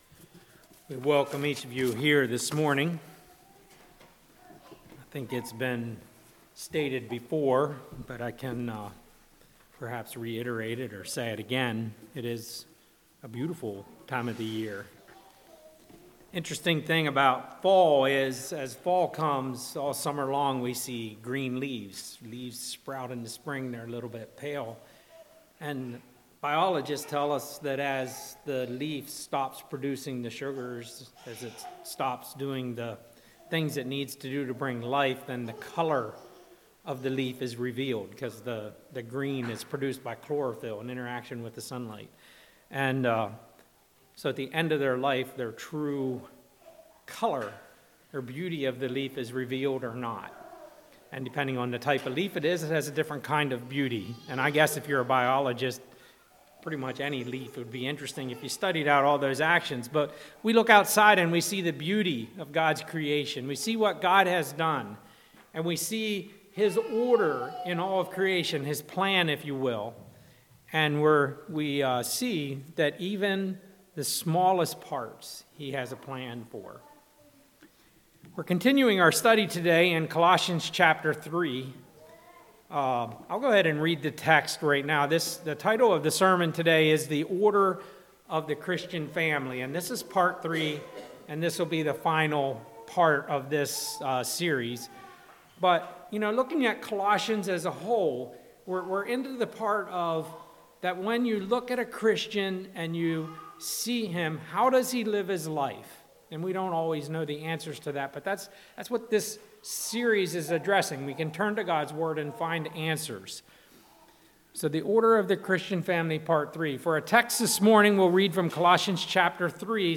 Colossians series Passage: Colossians 3:20-21 Service Type: Message « Living Lives Guided By The Holy Spirit Who Is Your Manager?